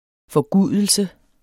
Udtale [ fʌˈguˀðəlsə ]